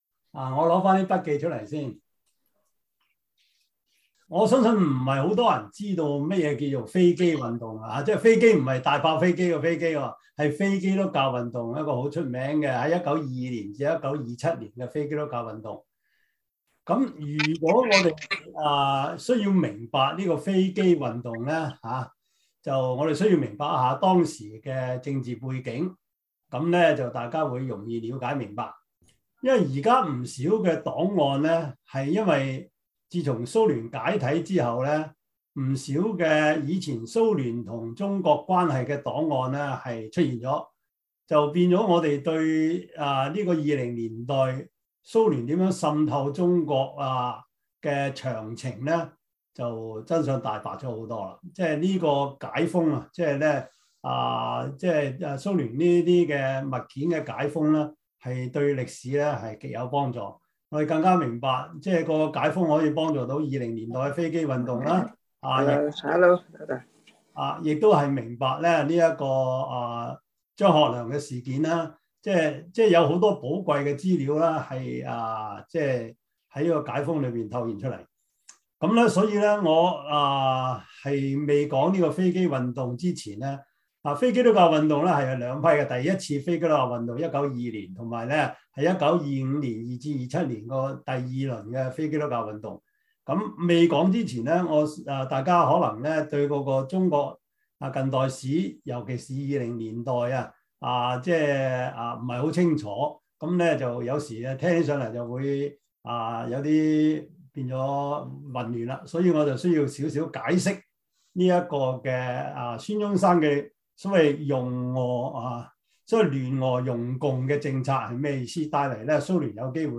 教會歷史 Service Type: 中文主日學 Preacher